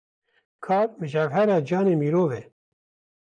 Read more human being Frequency B1 Pronounced as (IPA) /mɪˈɾoːv/ Etymology From an earlier *merom > merov.